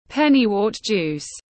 Nước ép rau má tiếng anh gọi là pennywort juice, phiên âm tiếng anh đọc là /ˈpen.i.wɜːt ˌdʒuːs/
Pennywort juice /ˈpen.i.wɜːt ˌdʒuːs/